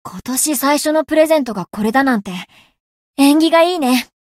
灵魂潮汐-莉莉艾洛-春节（送礼语音）.ogg